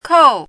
chinese-voice - 汉字语音库
kou4.mp3